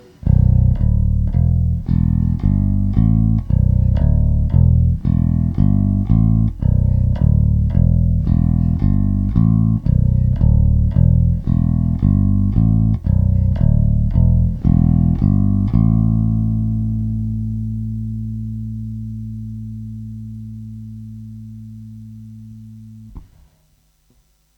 Das in den nachfolgenden .mp3 zu Hörende ist nur bei folgender Einstellung: Aktiv oder passiv, Bridge-PU im Humbucker-Modus + Tonblende komplett auf den Bridge-PU gestellt.
Die ersten 10s ist alles auf Mittenstellung aber Bridge-PU im Humbucker-Modus, aktiv, ab 13s ist dann die Tonblende komplett auf Bridge-Humbucker, aktiv gestellt.
Ist für mich irgendwie so, als ob sich beim Humbucker-Mode da irgendwas auslöschen würde oder so...